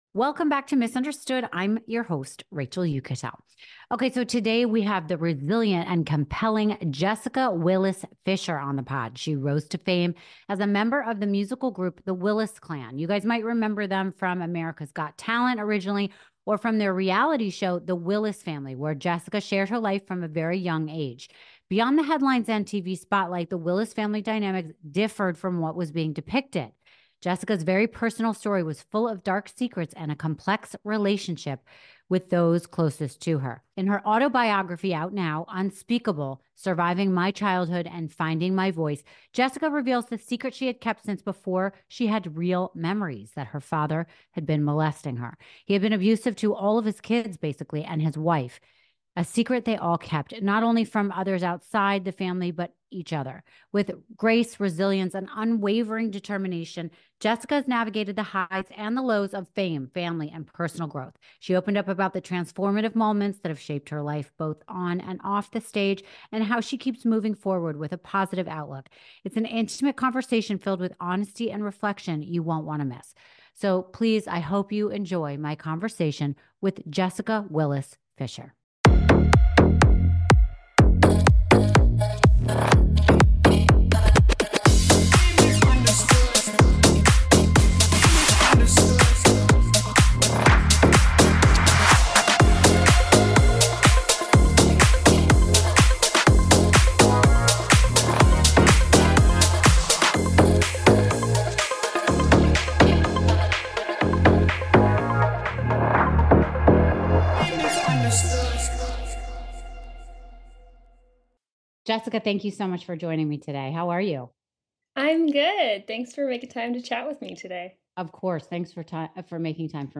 Get ready for an intimate conversation, it's an episode you won't want to miss.